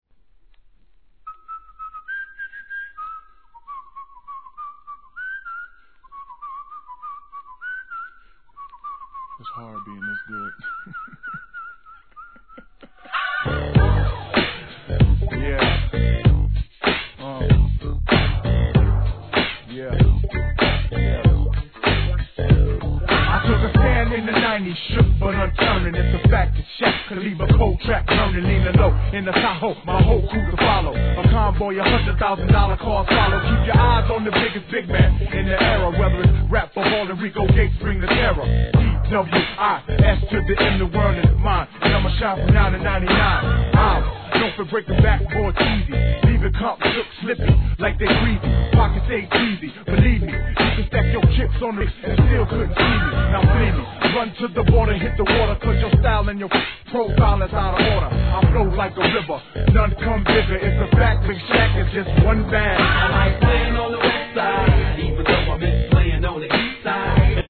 G-RAP/WEST COAST/SOUTH
時折り絡みつくヴォコーダ-が気持ちよすぎ!